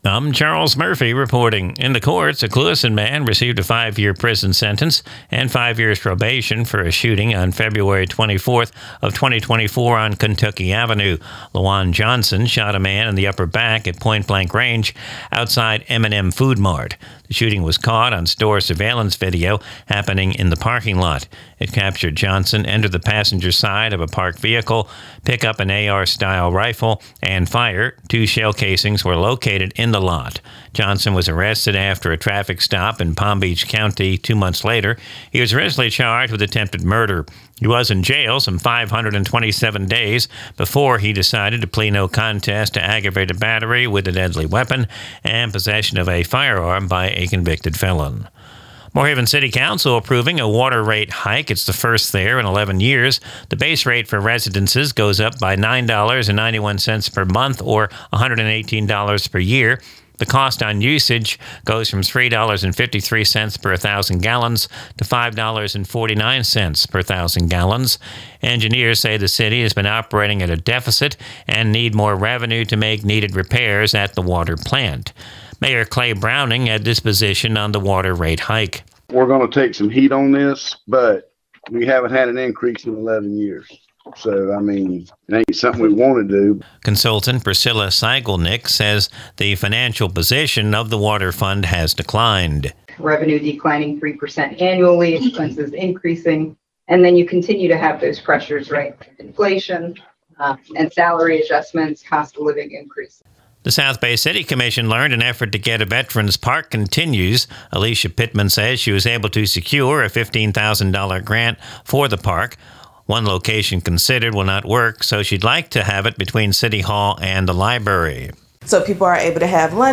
NEWS
Recorded from the WAFC daily newscast (Glades Media).